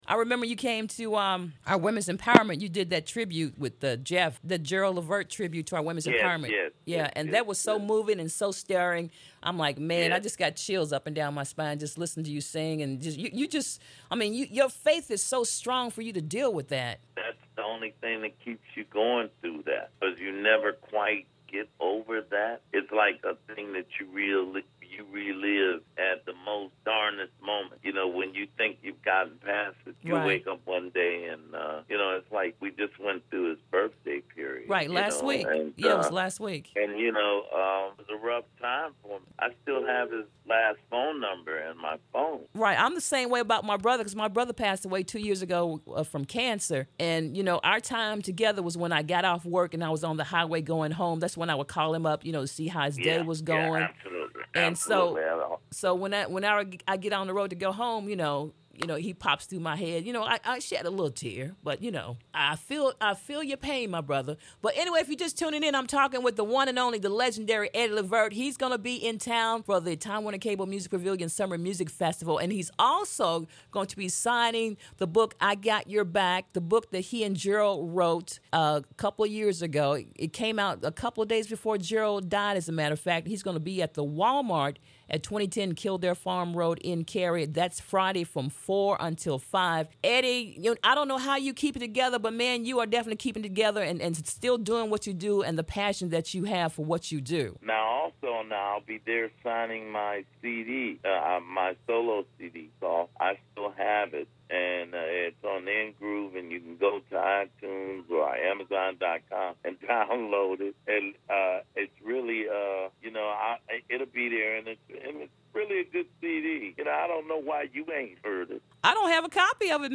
Interview: Eddie Levert
eddie-levert-interview.mp3